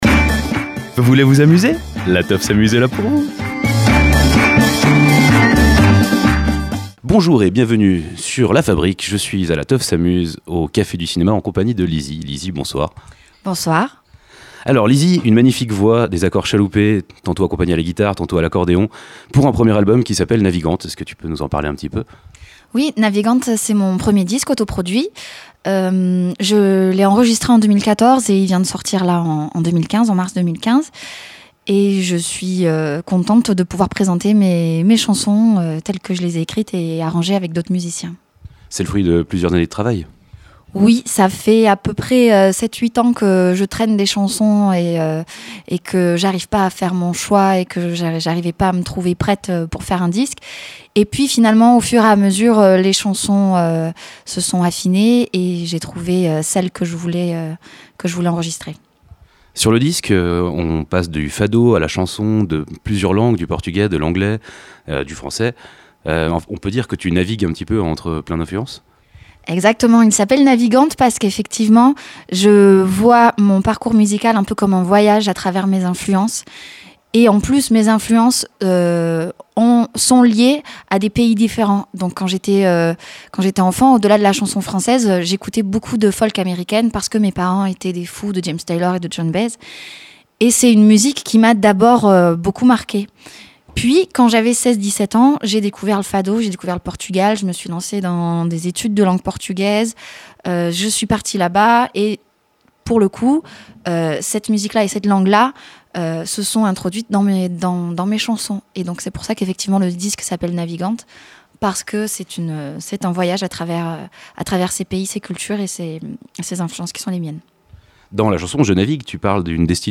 c’est la rencontre de la chanson française de la folk, et du fado. Un mélange très personnel porté par une voix envoûtante que nous vous proposons de découvrir à l’occasion de son passage à La Teuf s’amuse, en concert et en interview.